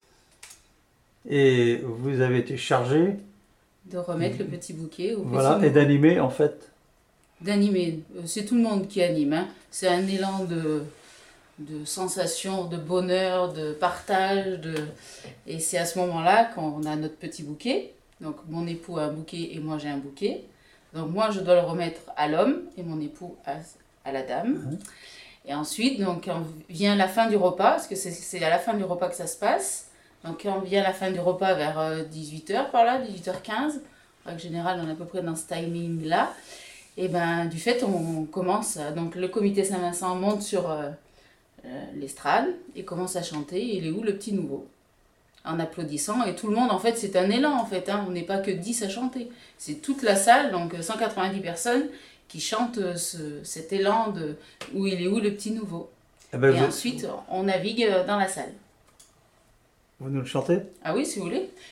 Enquête Enquête ethnologique sur les fêtes des bouviers et des laboureurs avec l'aide de Témonia
Catégorie Témoignage